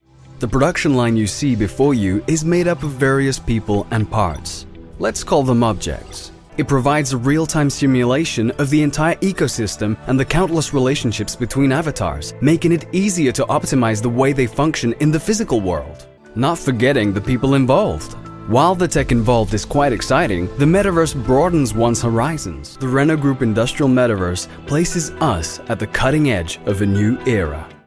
MALE VOICE OVER DEMOS AND EXTRACTS